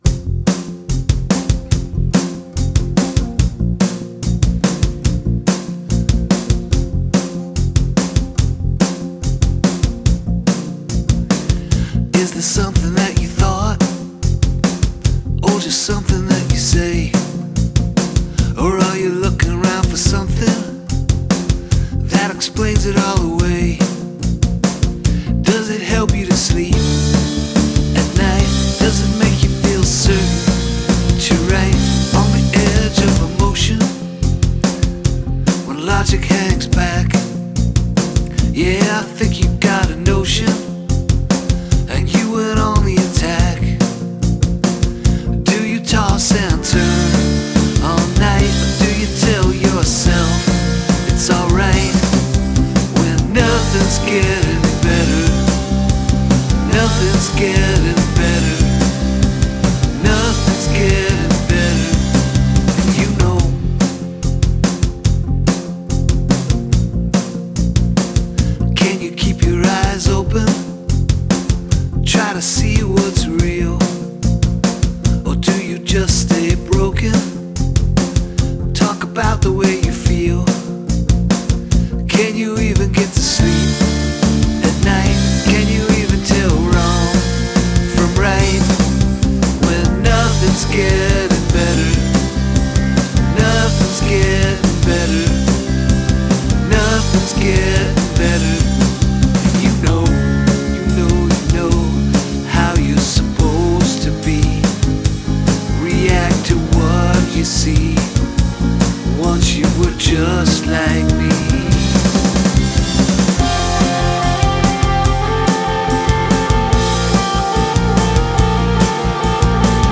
Love how it opens up for the last lines of the verses!